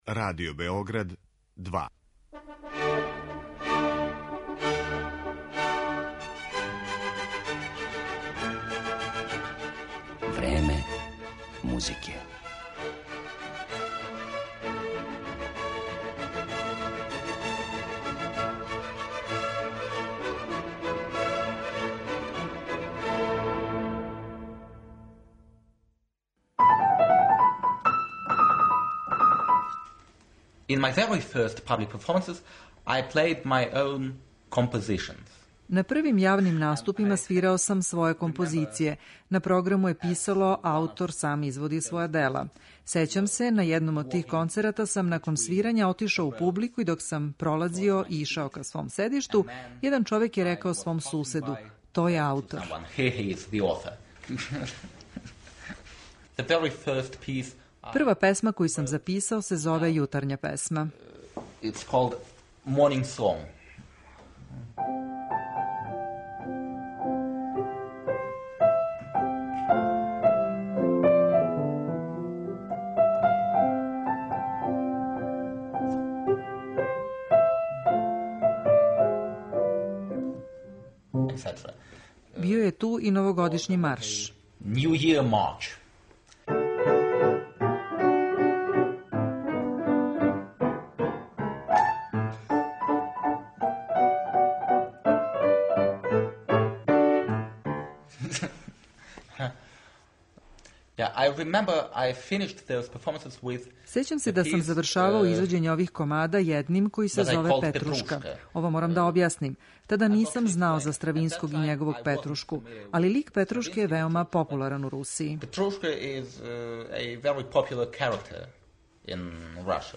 Овог славног руског пијанистиу моћи ћете да слушате како изводи композиције Фредерика Шопена, Јоханеса Брамса, Роберта Шумана, Лудвига ван Бетовена и Петра Иљича Чајковског.